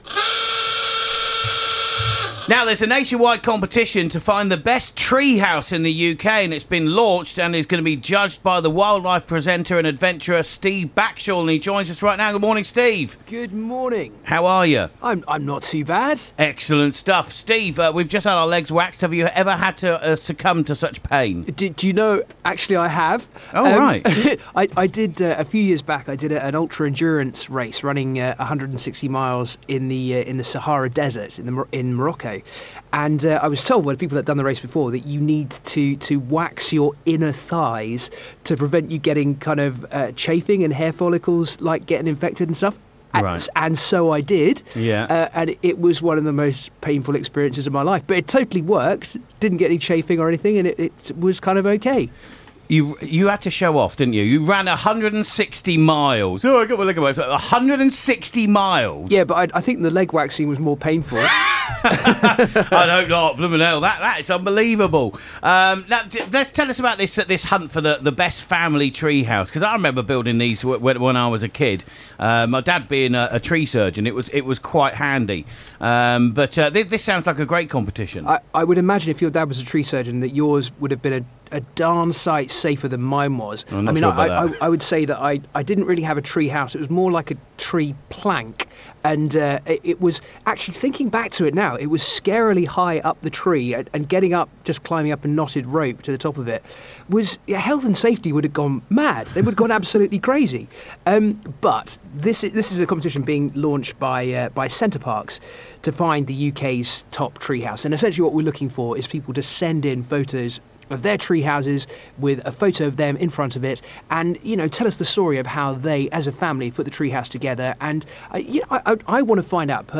Steve Backshall on Radio Yorkshire